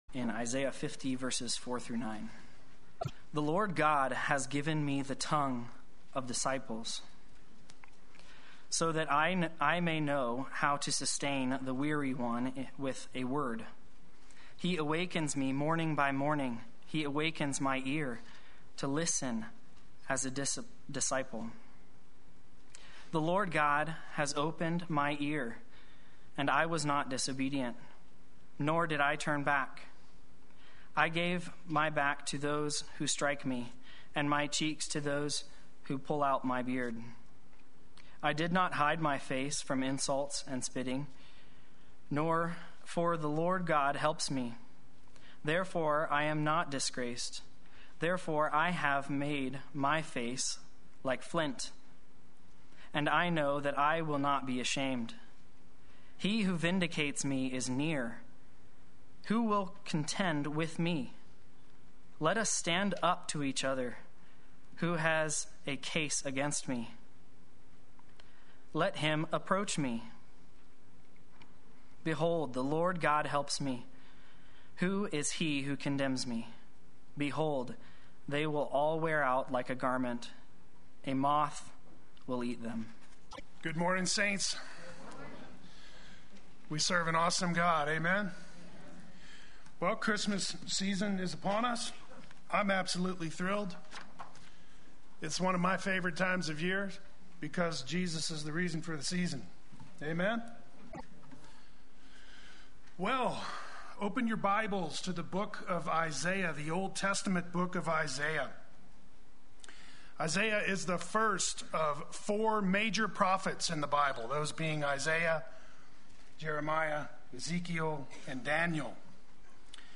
Play Sermon Get HCF Teaching Automatically.
Faithful Through Suffering Sunday Worship